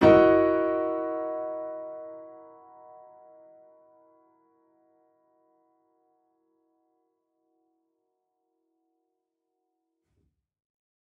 Index of /musicradar/gangster-sting-samples/Chord Hits/Piano
GS_PiChrd-E6min7.wav